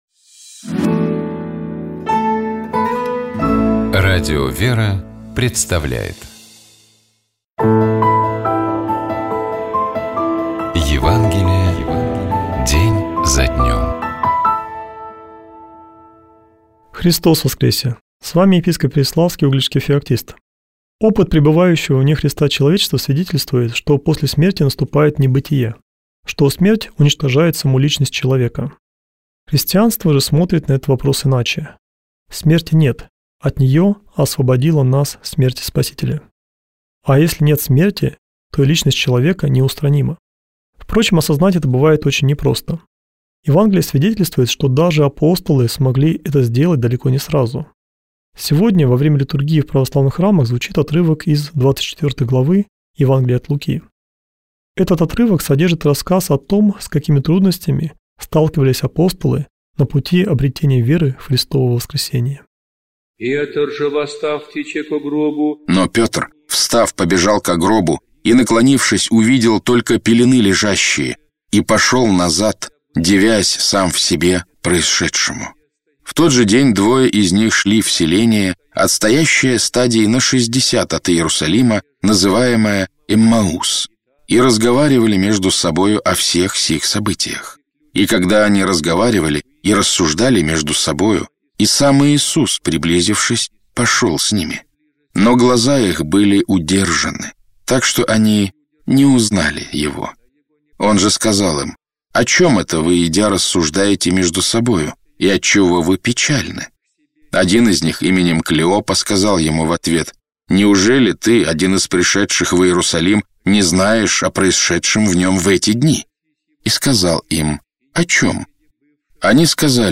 Читает и комментирует
епископ Феоктист ИгумновЧитает и комментирует епископ Переславский и Угличский Феоктист